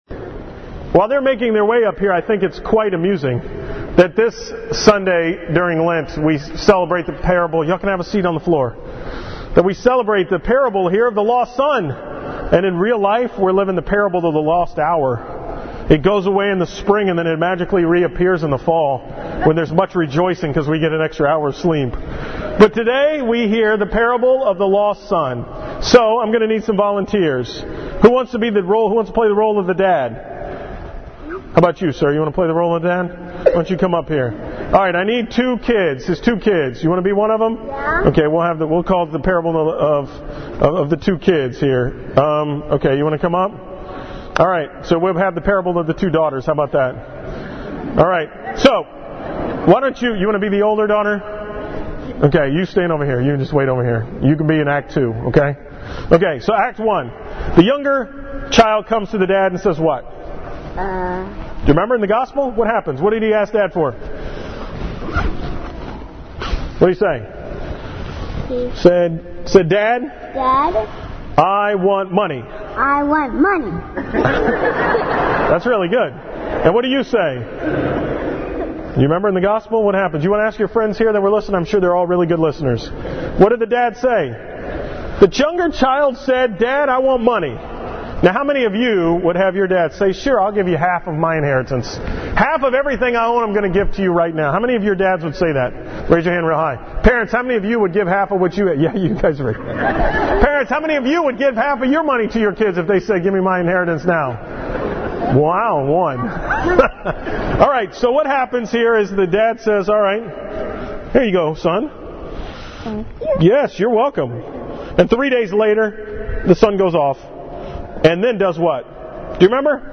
From the 9 am Mass on Sunday, March 10th 4th Sunday of Lent